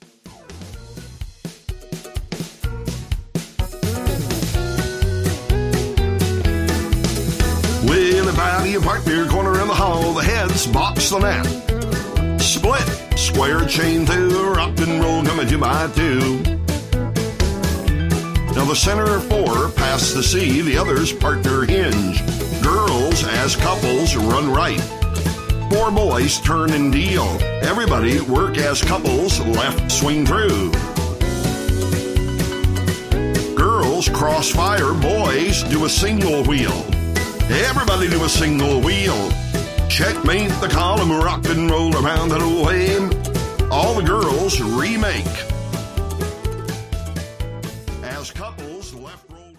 Category: Patter